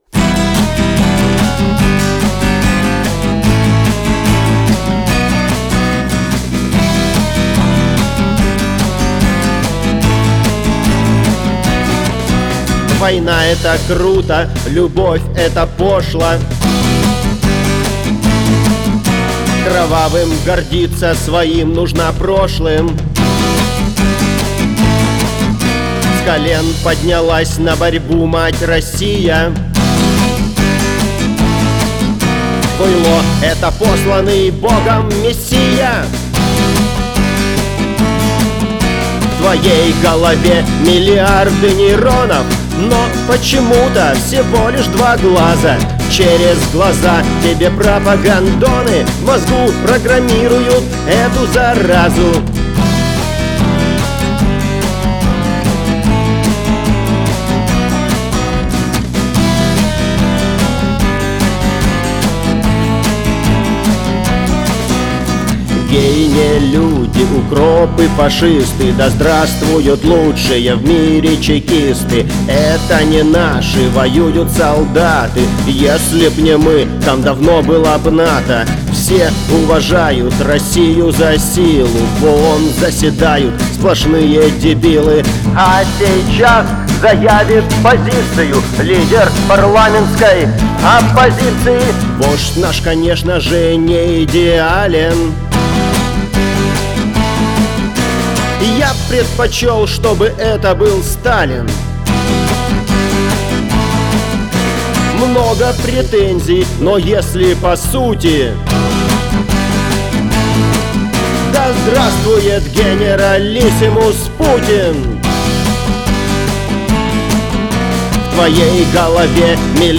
политический панк-рок